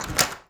door_lock_open_03.wav